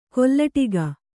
♪ kollaṭiga